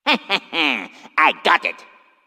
One of Waluigi's voice clips in Mario Party 6